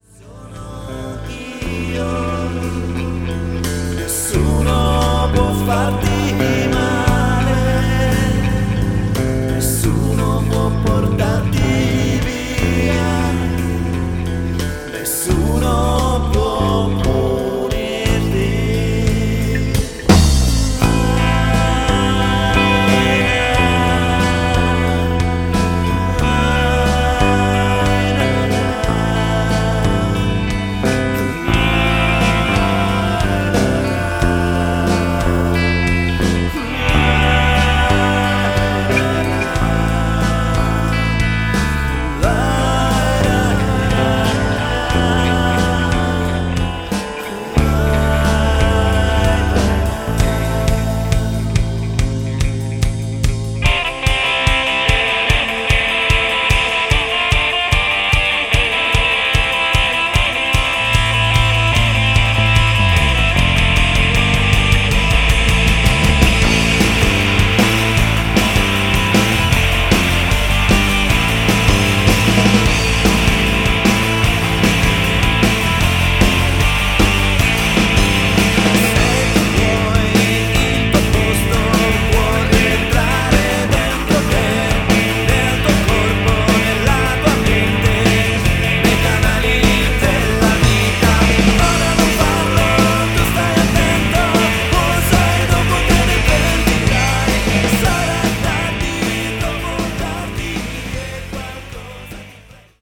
rock music
Genere: Rock.